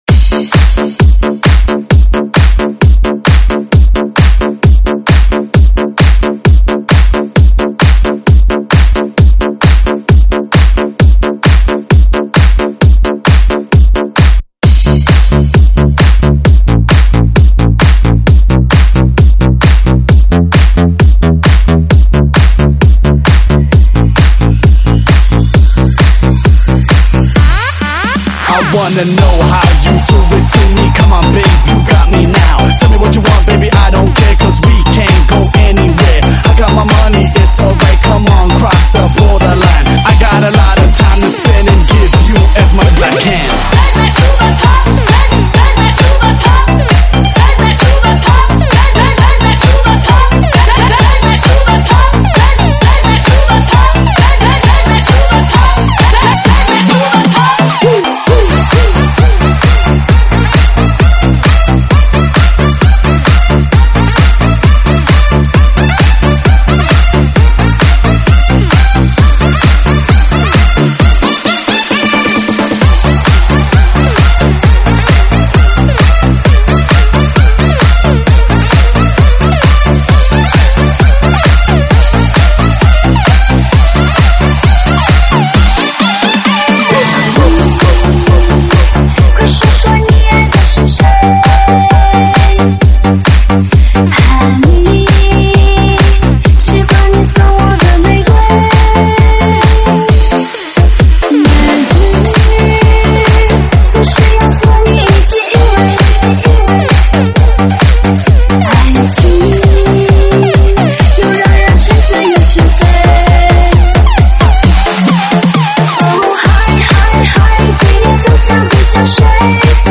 舞曲类别：电子Electro